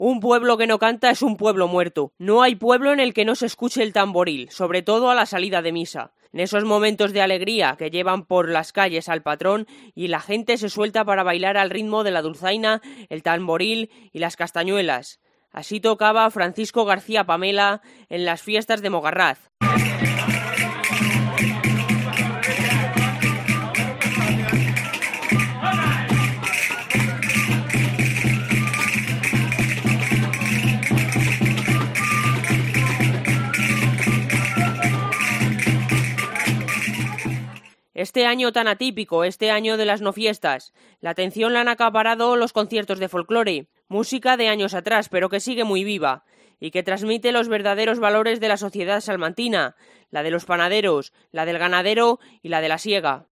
El músico tenía 90 años y era uno de los exponentes de la música tradicional charra.
No hay pueblo en el que no se escuche el tamboril, sobre todo a la salida de misa.
en las fiestas de Mogarraz de 2012